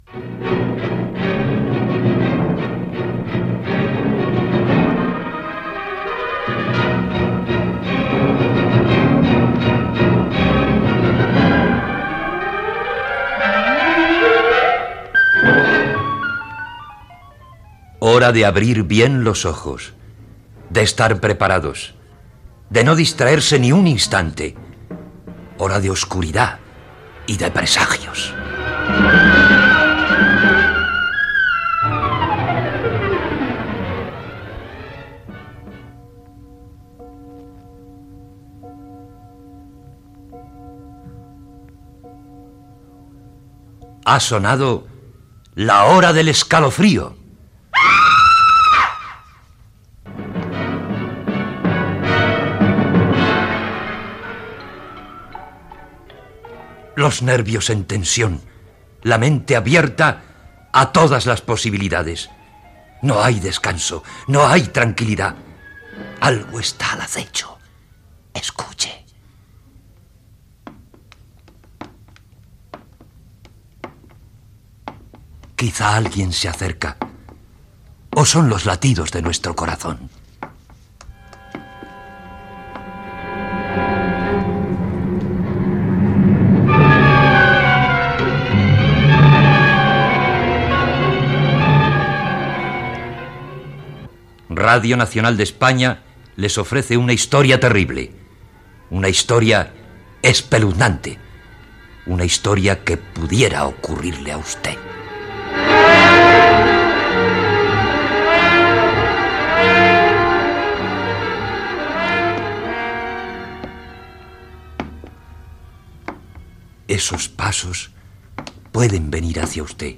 Presentació del programa, lectura d'un escrit de Groff Conklin , adaptació radiofònica de l'obra "El corazón delator" d'Edgar Alan Poe
Ficció